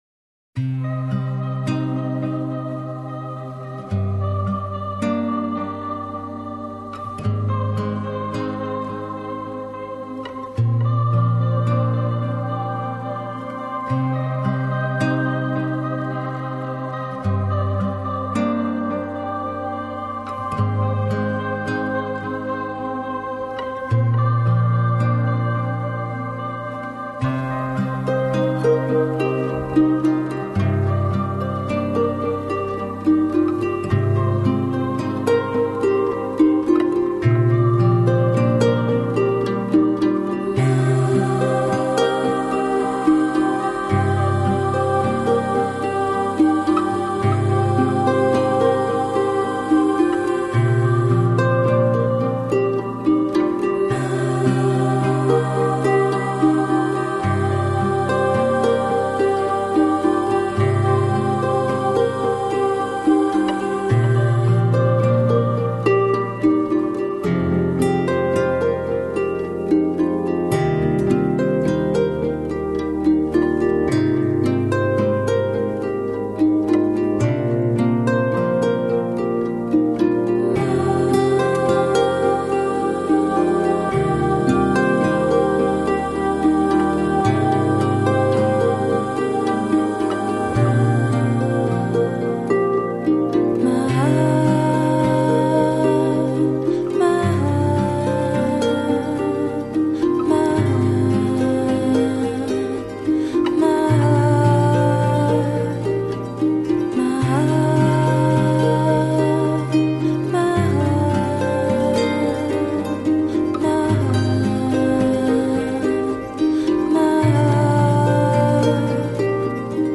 Жанр: New Age